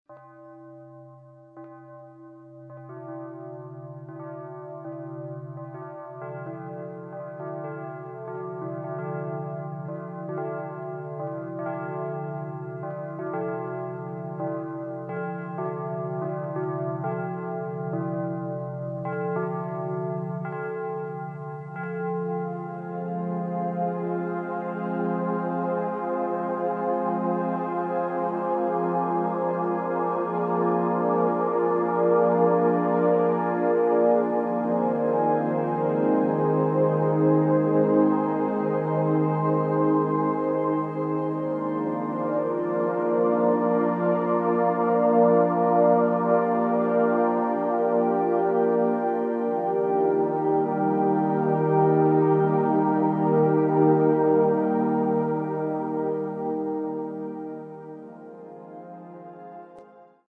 Music for inner peace and contemplation.